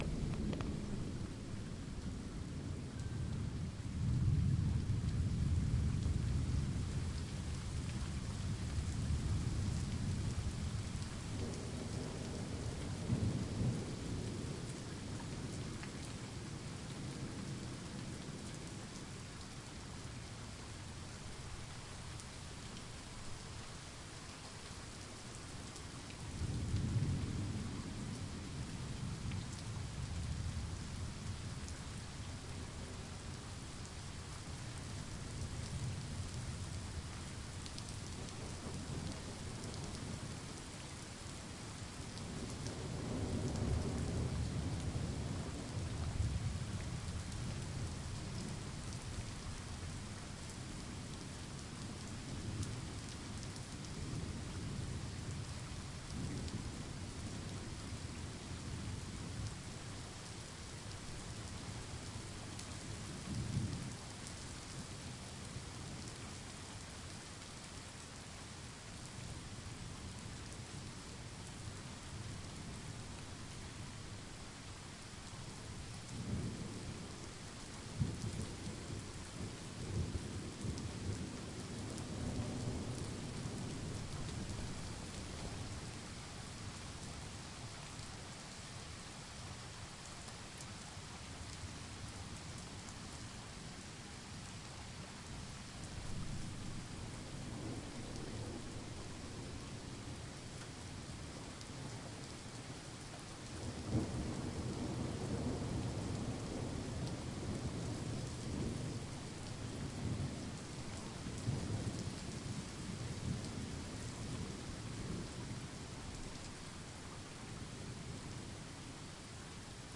雷霆" thunder3
描述：俄克拉荷马州2011年9月记录的雷暴
Tag: 风暴 天气 字段 记录 雷暴